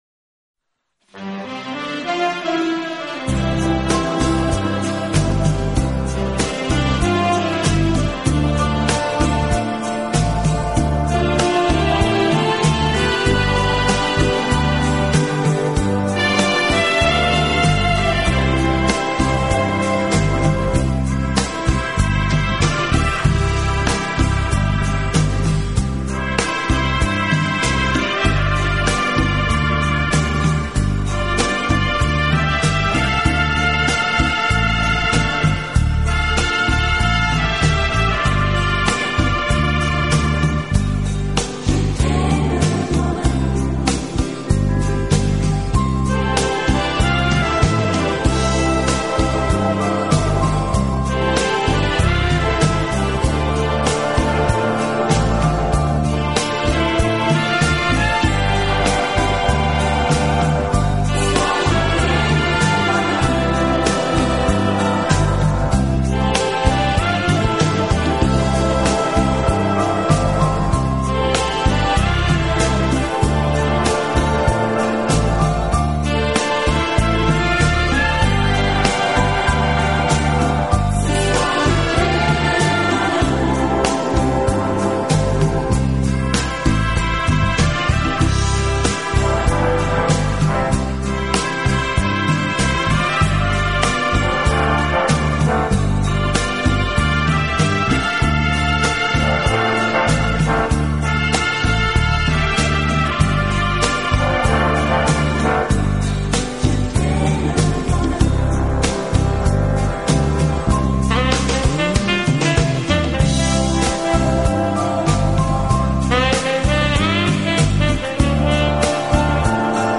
【轻音乐专辑】
的轻音乐团，以萨克斯管为主，曲目多为欢快的舞曲及流行歌曲改编曲。演奏
轻快、柔和、优美，带有浓郁的爵士风味。